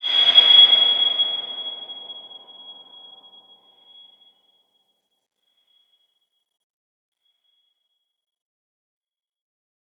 X_BasicBells-G#5-mf.wav